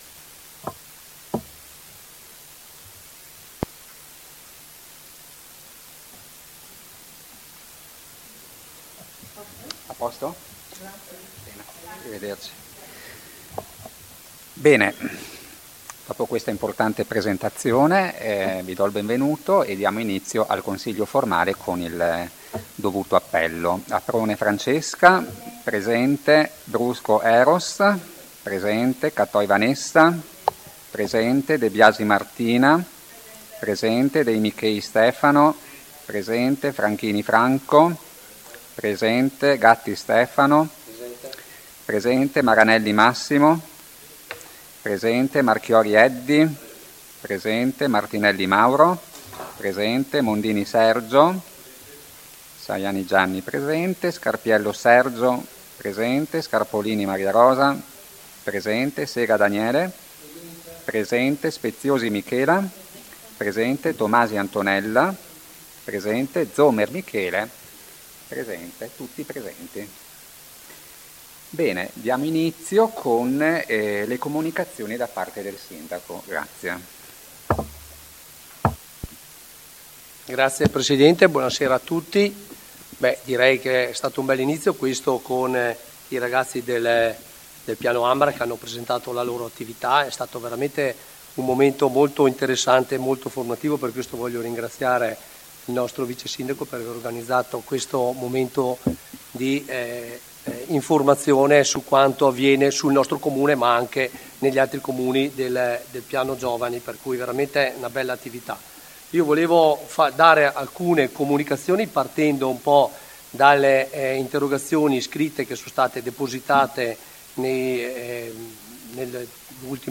AudioSedutaConsiglio.mp3